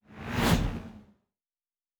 pgs/Assets/Audio/Sci-Fi Sounds/Movement/Fly By 01_5.wav at 7452e70b8c5ad2f7daae623e1a952eb18c9caab4
Fly By 01_5.wav